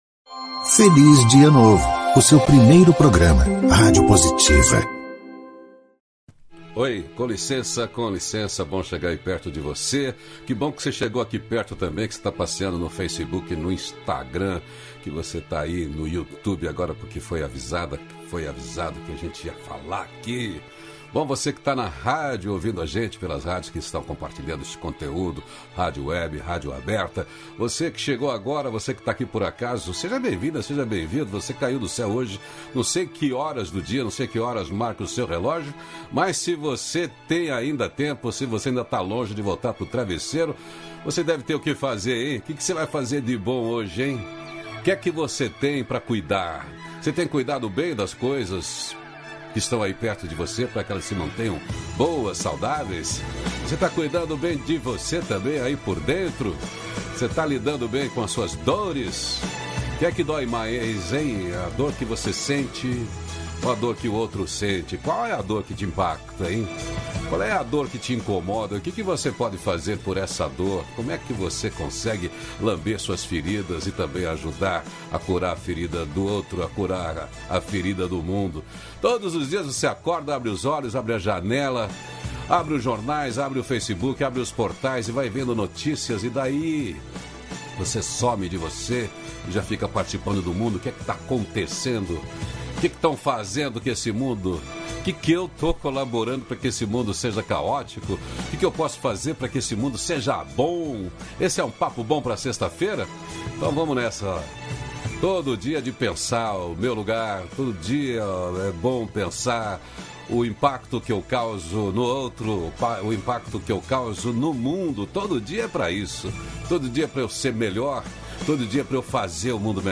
Hoje o diálogo nutritivo é sobre compaixão diante dos conflitos externos, tragédias e violência que nos impactam emocionalmente, mesmo quando distantes.